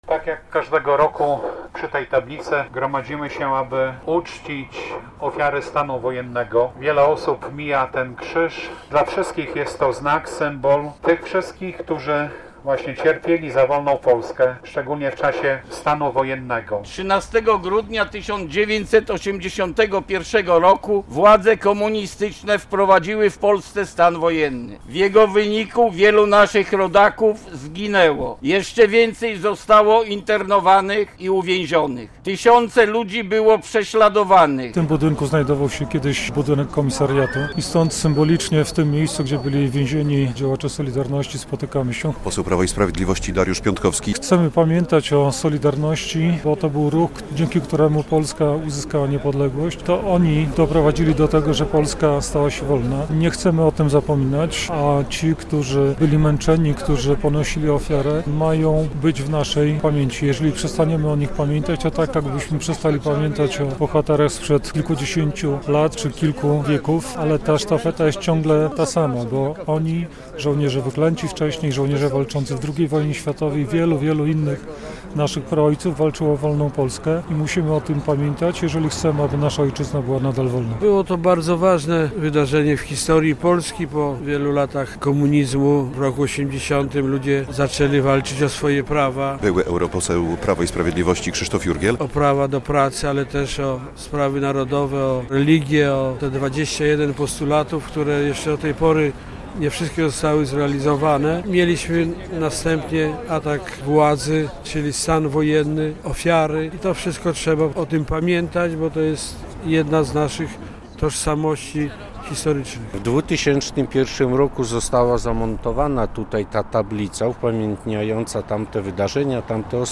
Obchody 43. rocznicy stanu wojennego - relacja